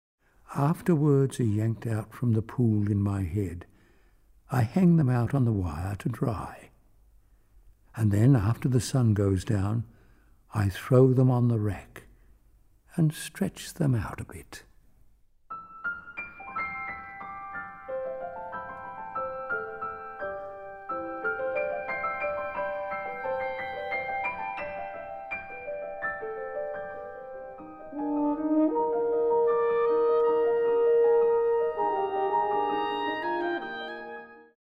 Studio 420, Ferry Road, Brisbane, 6 – 8 February 2012